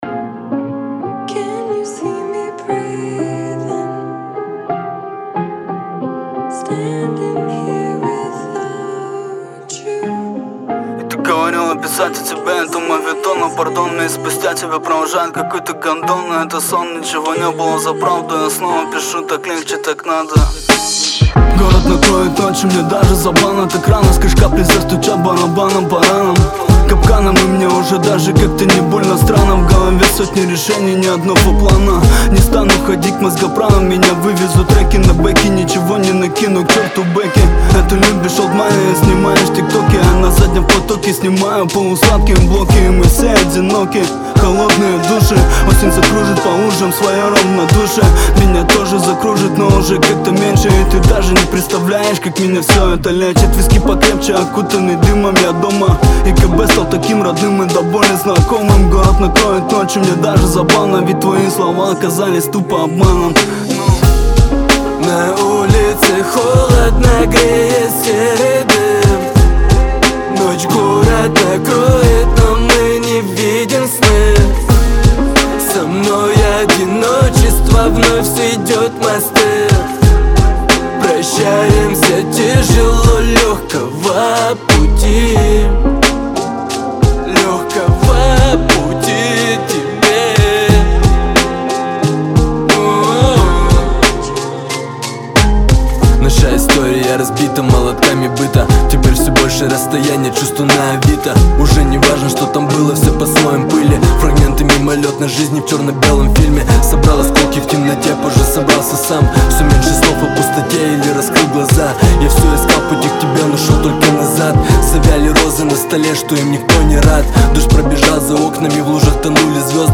Жанр: rap